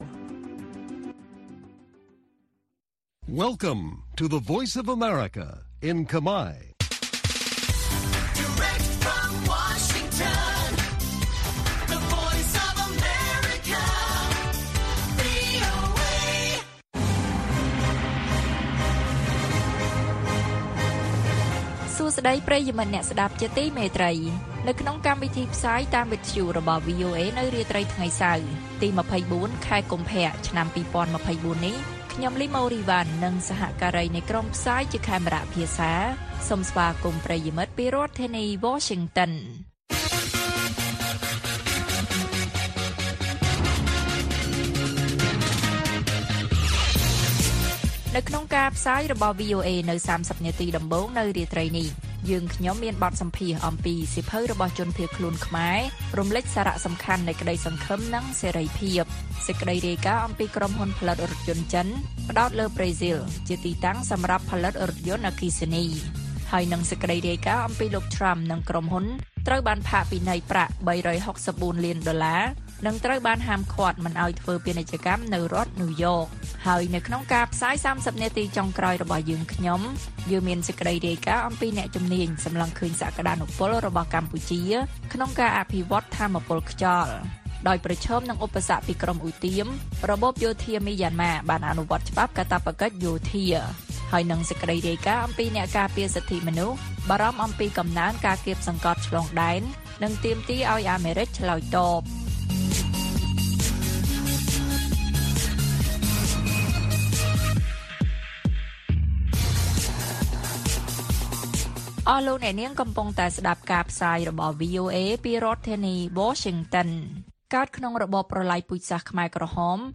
ព័ត៌មានពេលរាត្រី ២៤ កុម្ភៈ៖ បទសម្ភាសន៍៖ សៀវភៅរបស់ជនភៀសខ្លួនខ្មែររំលេចសារៈសំខាន់នៃក្តីសង្ឃឹមនិងសេរីភាព